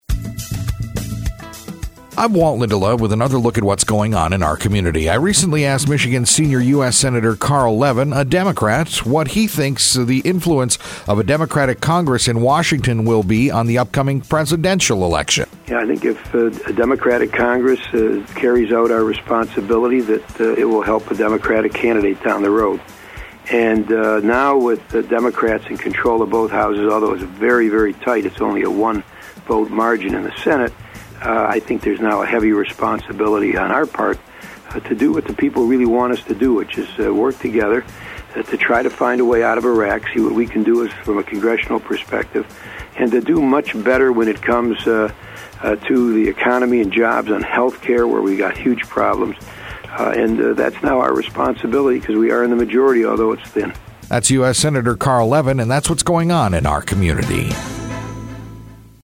INTERVIEW: U.S. Senator Carl Levin